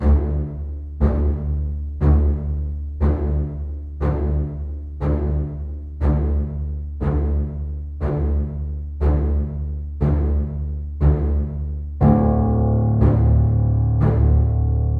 更新猫鼠追逐 bgm